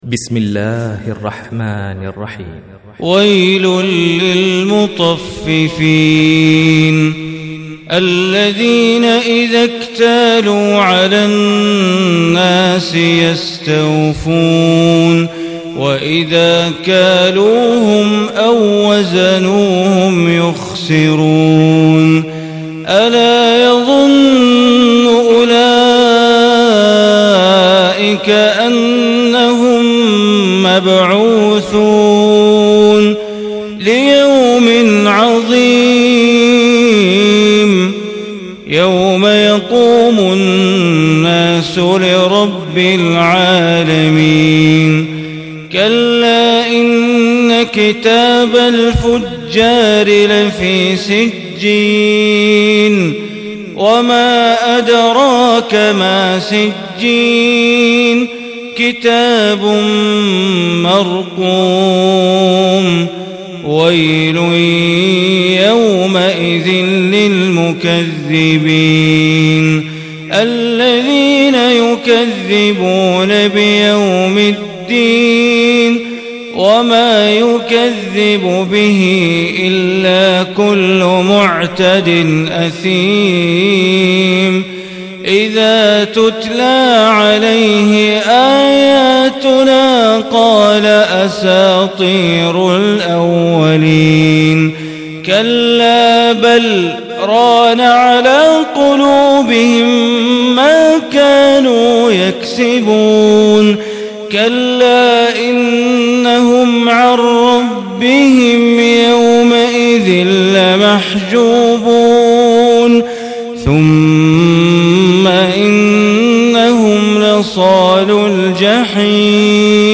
Surah Mutaffifin MP3 Recitation